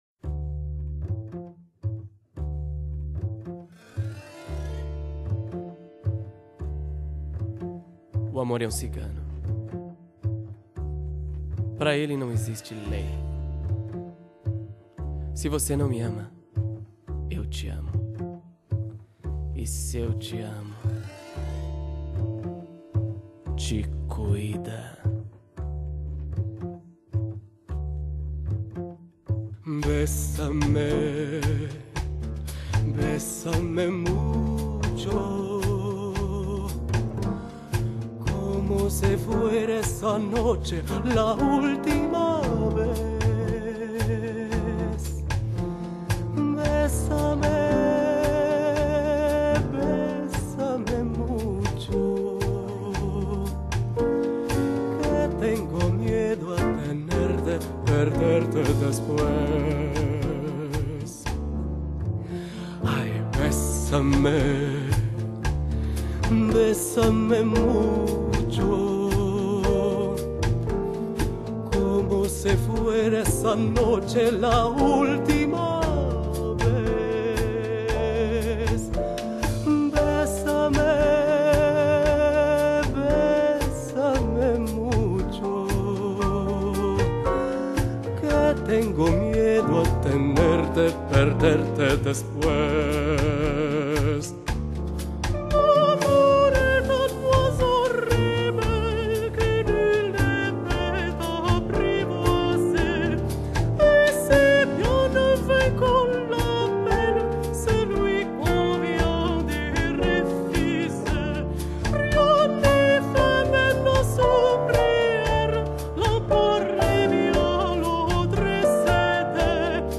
流派：jazz, classic, bossa nova，crossover, fushion
他忽男忽女、这段美声那段流行、一下真音一下假音、时而英文时而葡语的多端变化，